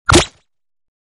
EsquireHit2.ogg